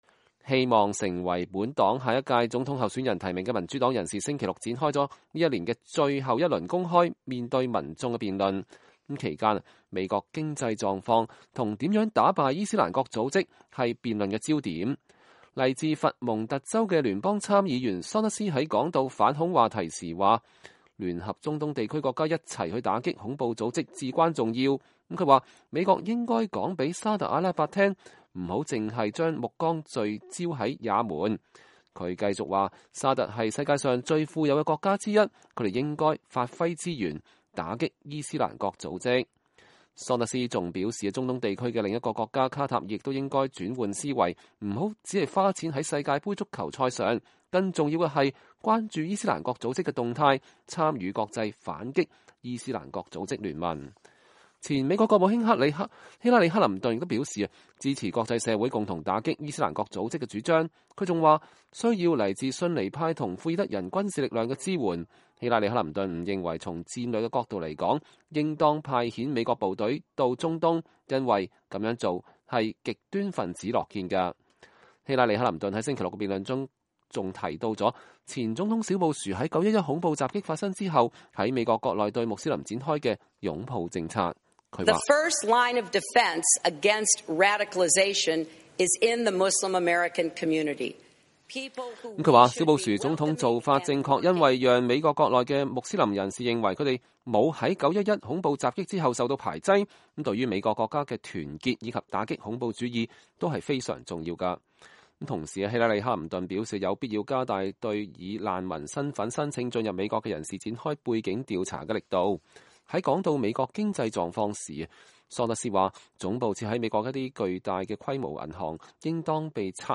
2015-12-20 美國之音視頻新聞: 美國總統大選民主黨辯論聚焦經濟與反恐